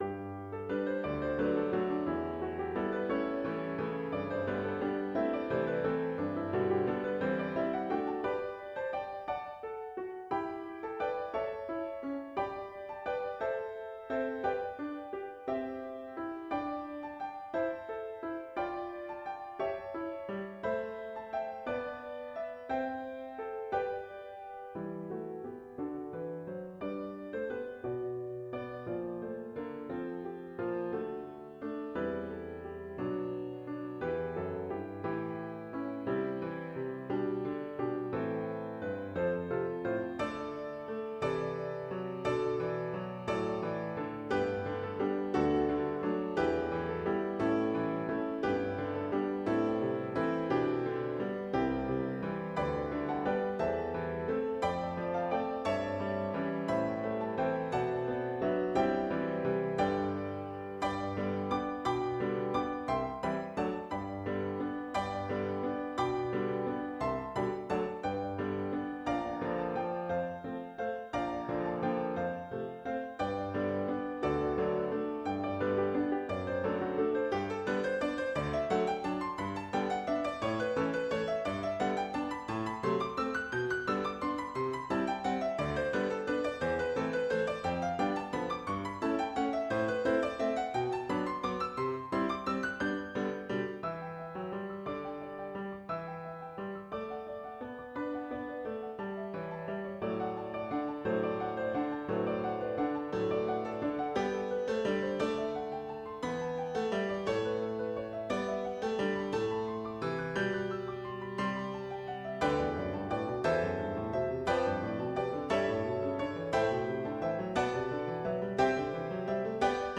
A Waltz
I never thought I would find myself writing waltzes, but as I composed this music, it came to me as one grand Viennese waltz.
This music is written in Rondo form, the dominant melody repeating in different variations. The tempo Vivace gioioso, lively and joyfully